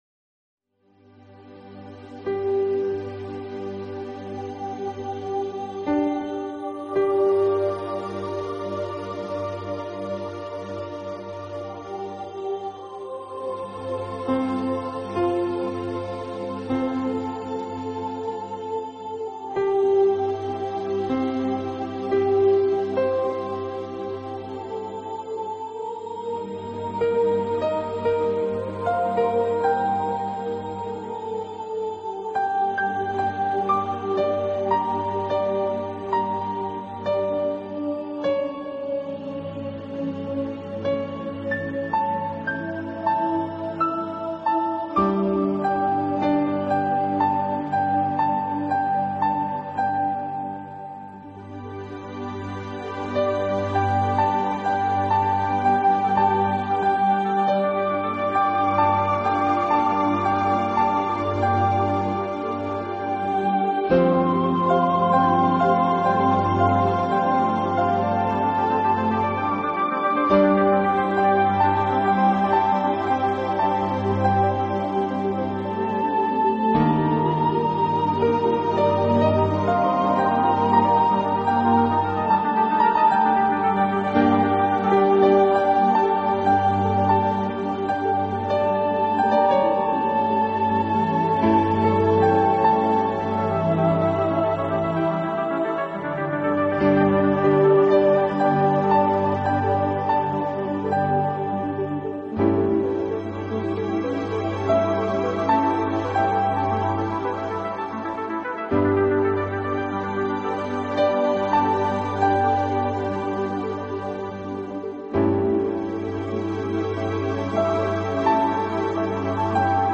些激动，音乐平缓却非常的优雅和绚美，梦幻般的意境已经由音乐的开始而铺就